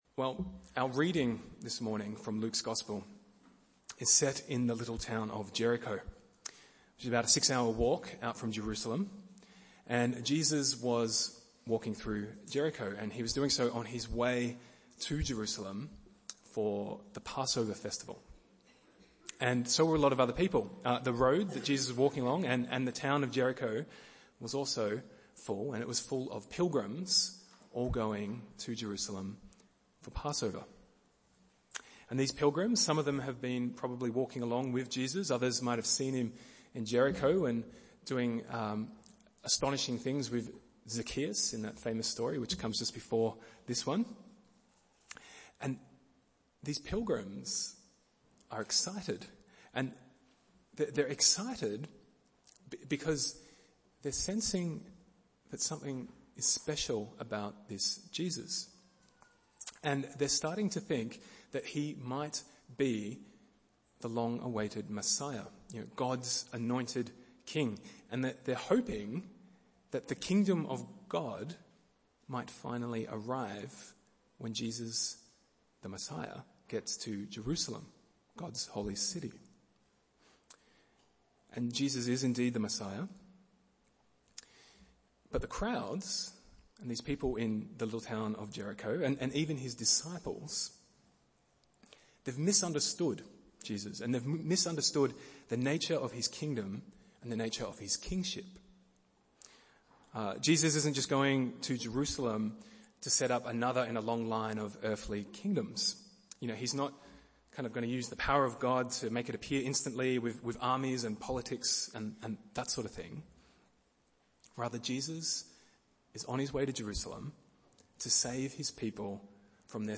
Bible Text: Luke 19:11-27 | Preacher